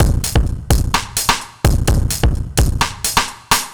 Index of /musicradar/analogue-circuit-samples/128bpm/Drums n Perc
AC_SlackDrumsA_128-03.wav